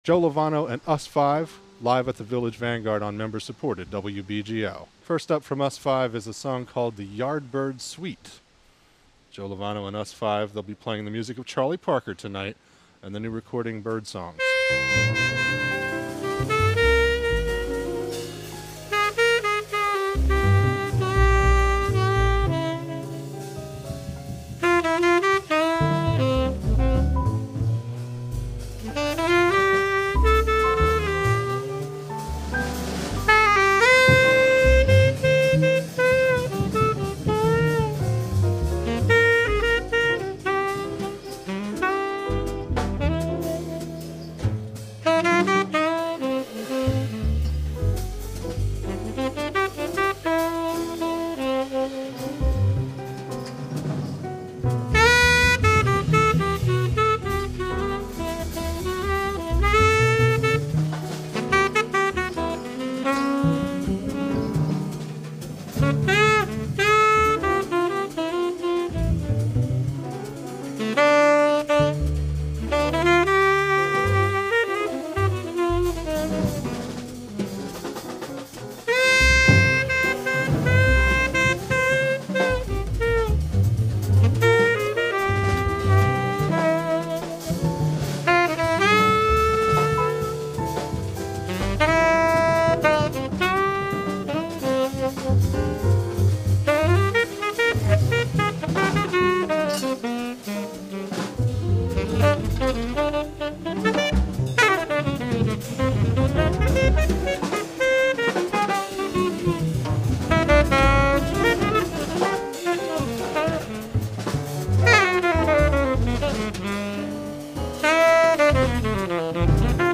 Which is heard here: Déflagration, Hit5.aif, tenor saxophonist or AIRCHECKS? tenor saxophonist